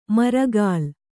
♪ maragāl